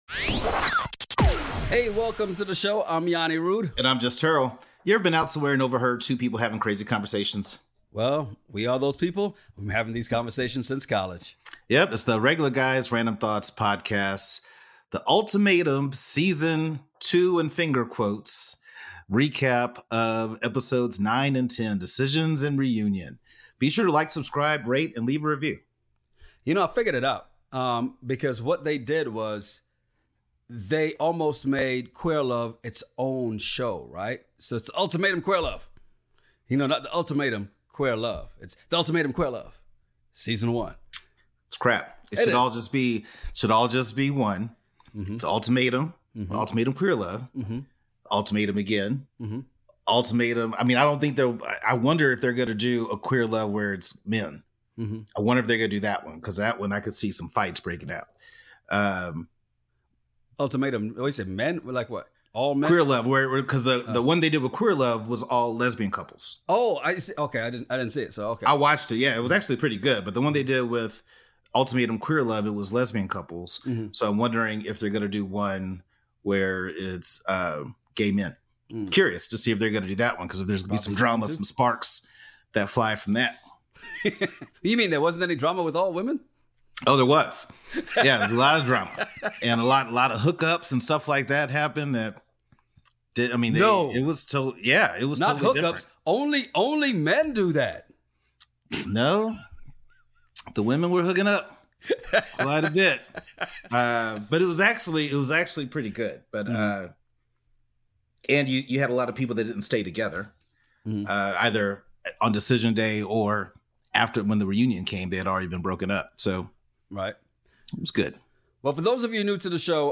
The couples decide then it’s back for the reunion. Ever been somewhere and overheard two guys having a crazy conversation over random topics?